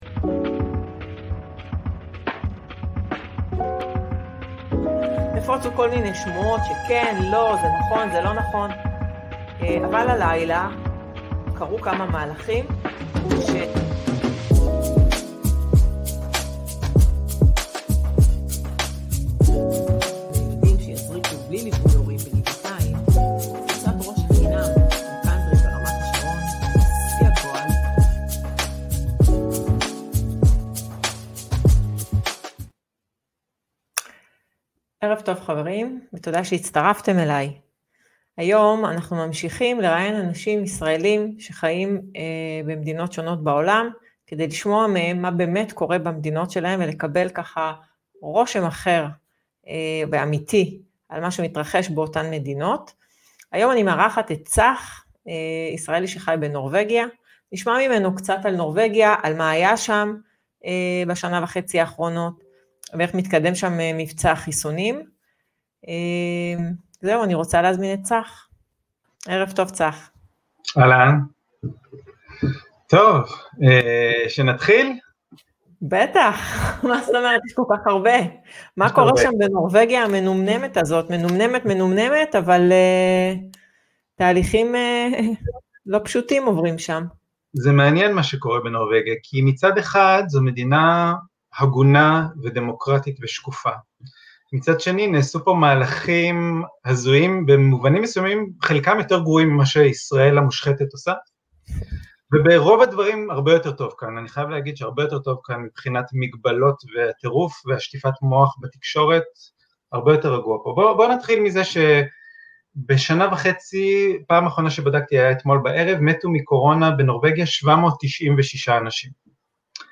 שיחה